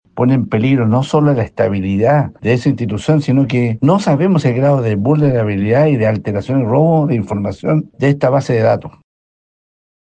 Por su parte, el diputado Daniel Lilayu, miembro de la misma comisión, advirtió que no solo se compromete la operatividad del ISP, sino también la integridad de los datos que allí se manejan.